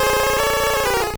Cri de Feunard dans Pokémon Or et Argent.